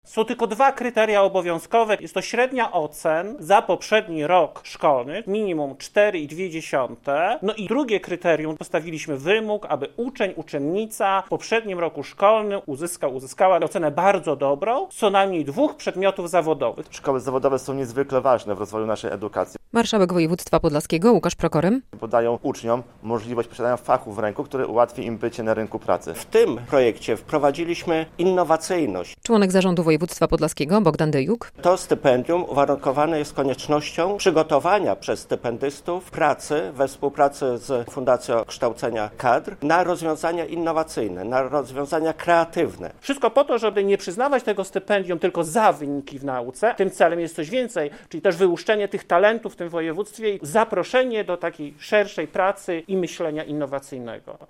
Jakie warunki trzeba spełnić, by otrzymać stypendium - relacja